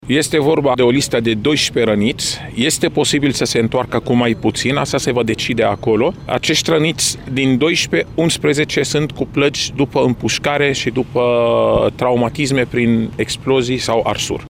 Coordonatorul misiunii umanitare, secretarul de stat în Ministerul de Interne, doctorul Raed Arafat a precizat cã cei 11 au ajuns în România ca urmare a solicitãrii autoritãților ucrainene: